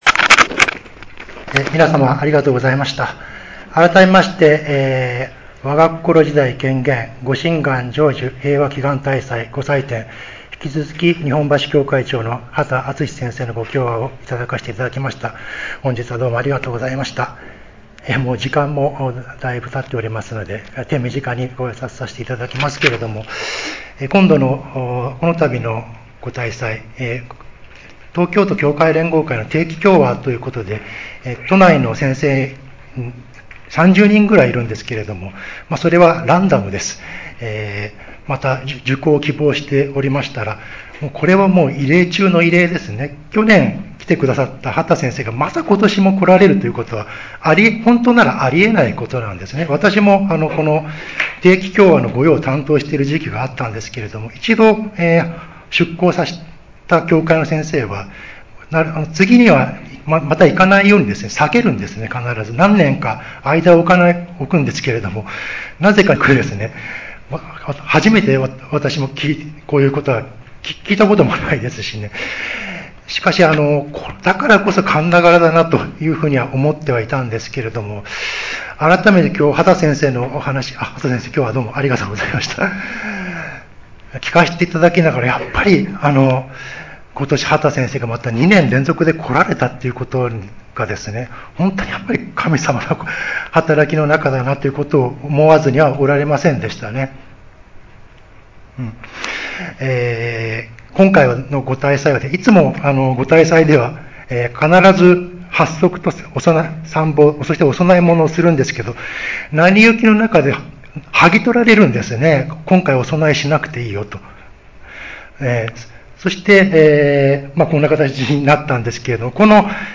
25.08.11 平和祈願大祭祭主挨拶